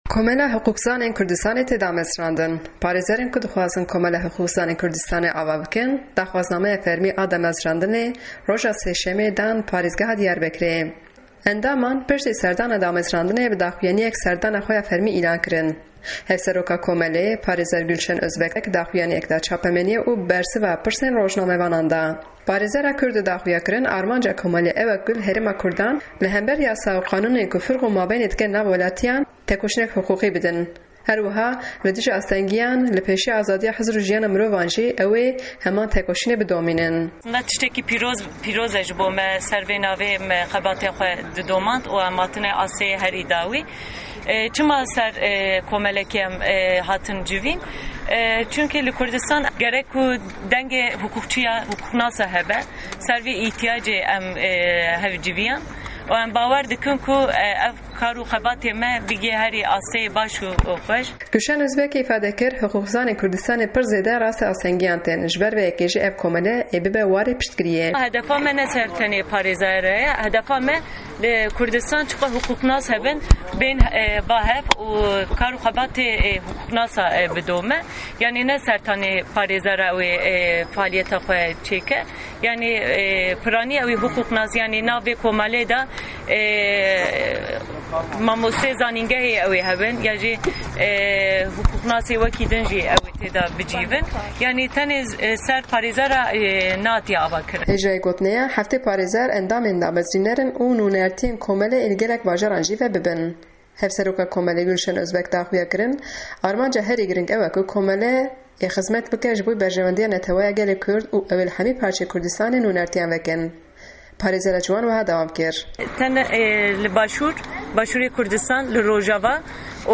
Peyamnêr